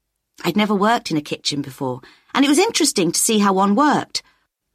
So much so that, as the following examples demonstrate, it also happens after vowels, which is more surprising and not very often described in the literature.